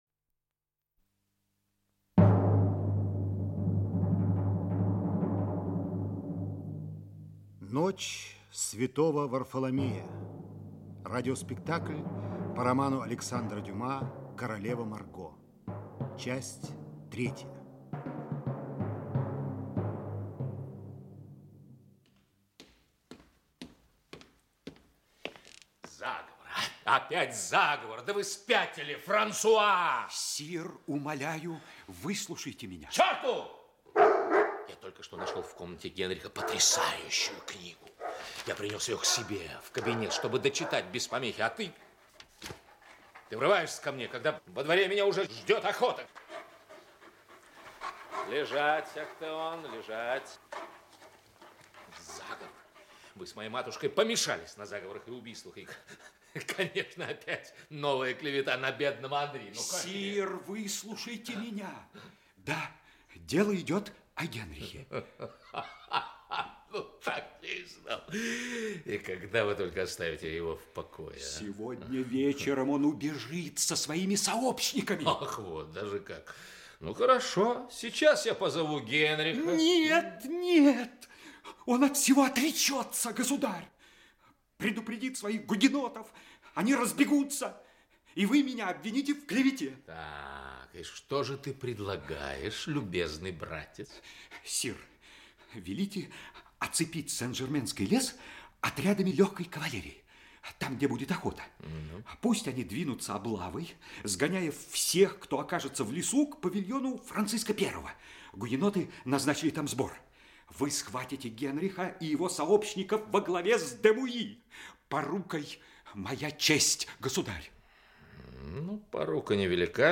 Aудиокнига Королева Марго (спектакль) Часть 3-я Автор Александр Дюма Читает аудиокнигу Актерский коллектив.